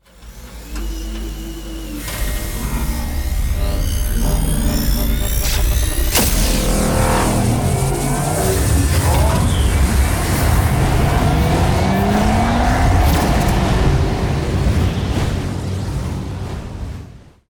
jump.ogg